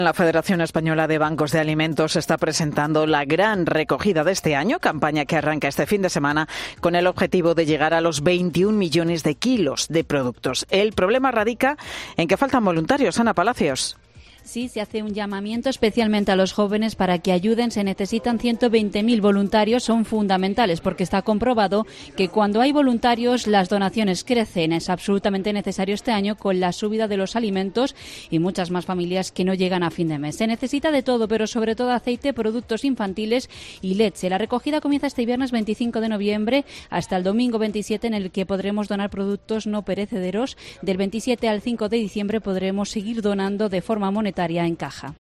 Nueva Gran Recogida del Banco de Alimentos. Crónica